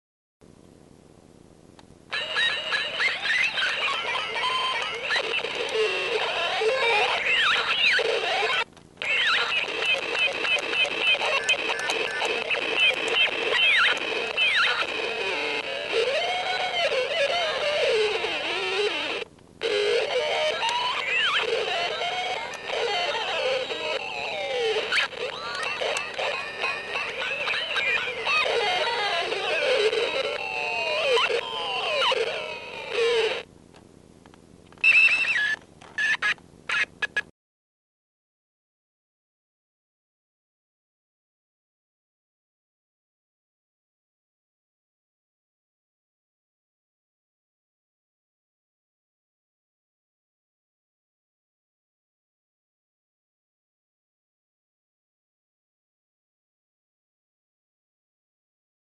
A circuit bent guitar toy (Listen)
Another kids toy with two 'skew' knobs on it which affect the pitch.
Guitar (bad recording).mp3